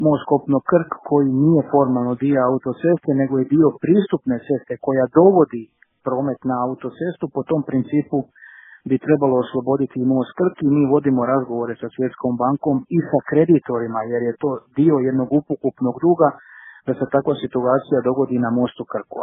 Odluka bi trebala biti donesena do kraja godine, rekao je u razgovoru za Media servis ministar mora, prometa i infrastrukture Oleg Butković.